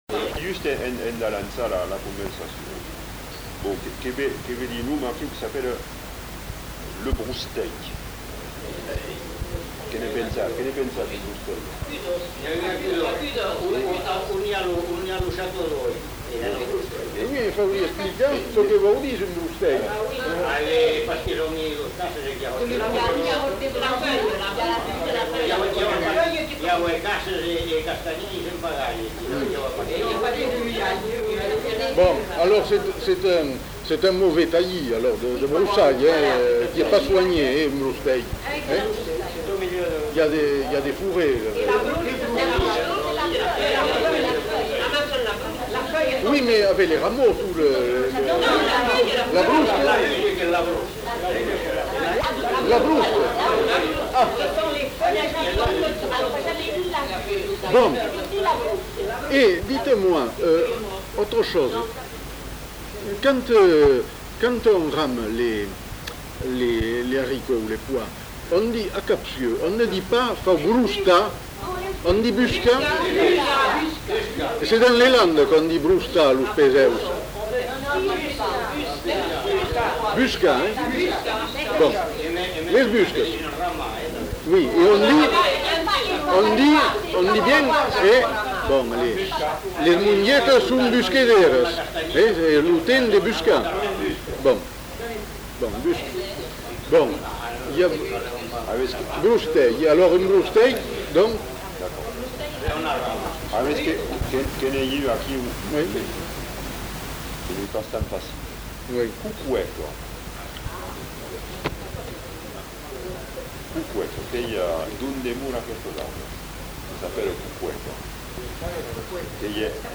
Aire culturelle : Bazadais
Lieu : Bazas
Genre : témoignage thématique